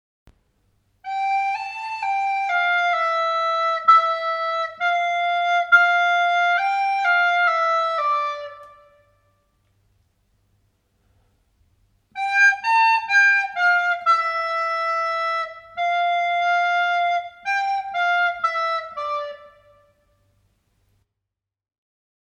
A ligadura
A que aparece na partitura, é a ligadura de unión, e permite que un son se una con outro do mesmo nome e altura.